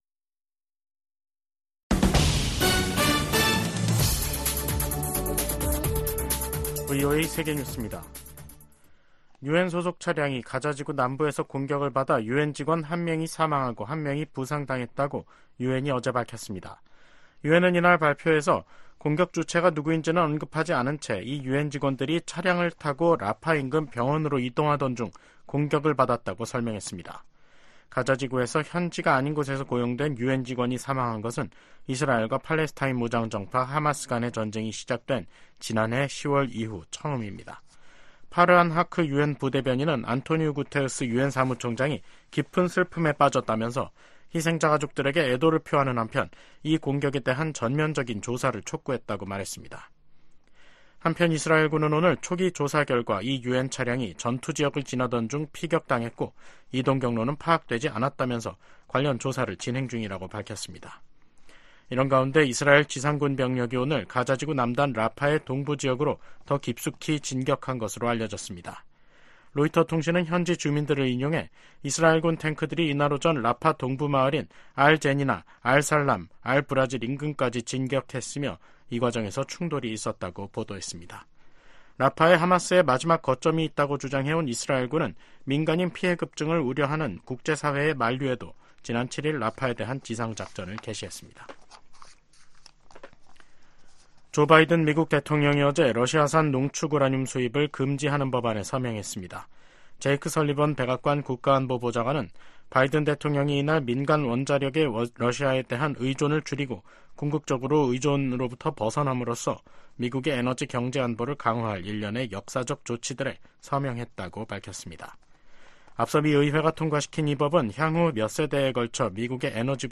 VOA 한국어 간판 뉴스 프로그램 '뉴스 투데이', 2024년 5월 14일 3부 방송입니다. 러시아가 철도를 이용해 북한에 유류를 수출하고 있다는 민간 기관 분석이 나온 가운데 실제로 북러 접경 지역에서 최근 열차 통행이 급증한 것으로 나타났습니다. 러시아가 올해 철도를 통해 25만 배럴의 정제유를 북한에 수출했다는 분석이 나온 데 대해 국무부가 북러 협력 심화에 대한 심각한 우려를 나타냈습니다.